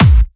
Bassdrums
ED Bassdrums 10.wav